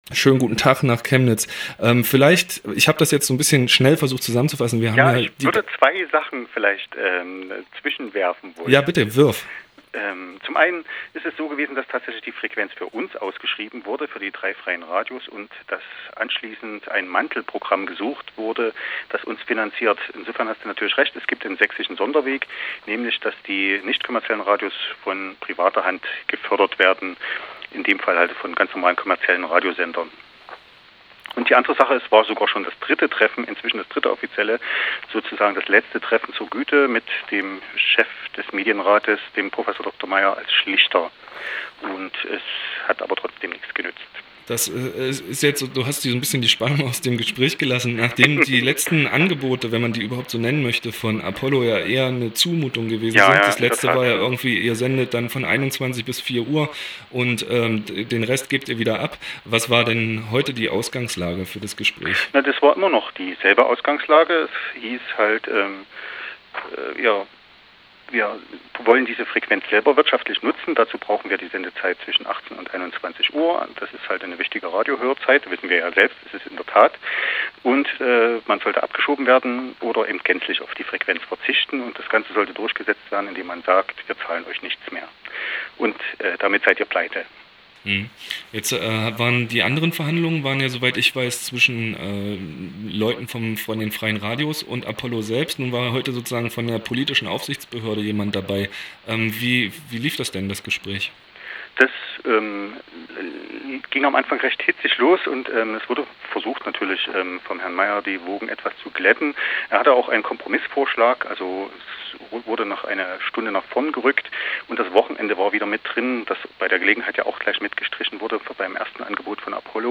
Die Verhandlungen zwischen den sächsischen Freien Radios und Apollo Radio sind endgültig gescheitert. Dazu ein Gespräch